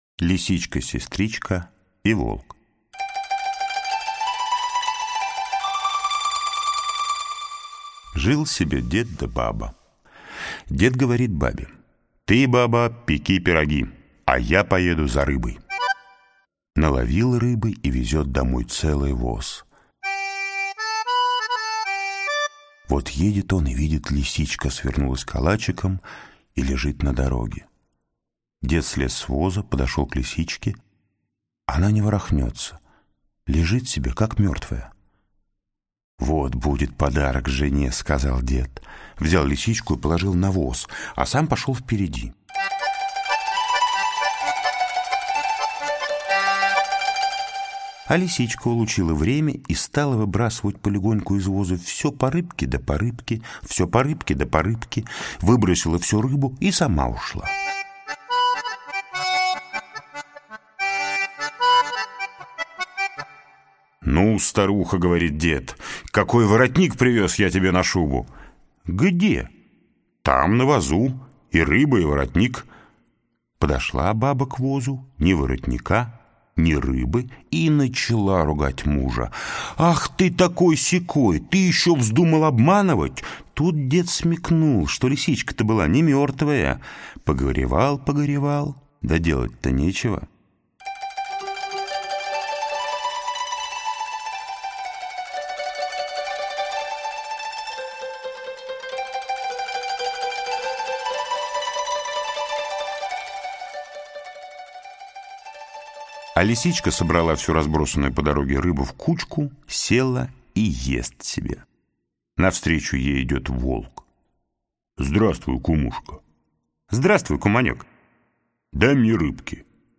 lisichka-sestrichka_i_volk_russkie_narodnye_skazki_-_audioskazka_ipleer.fm_1.mp3